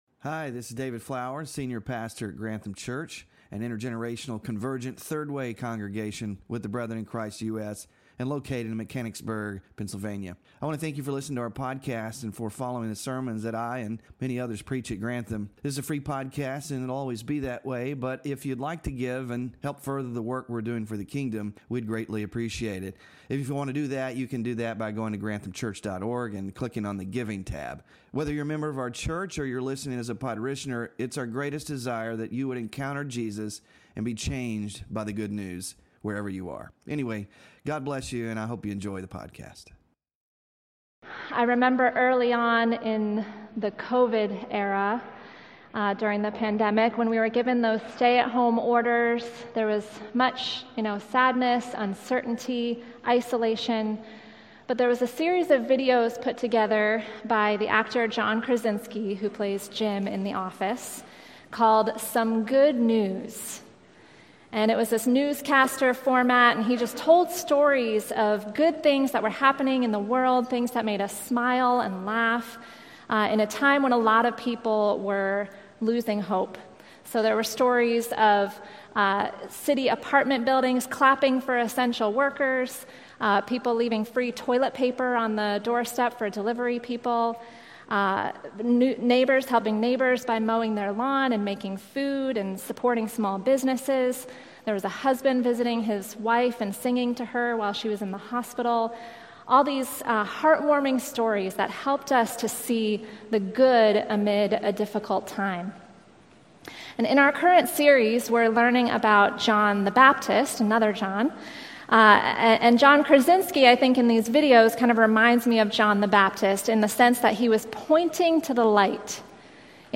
Sermon Focus: John the Baptist knew his identity and calling.